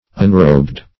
unrobbed.mp3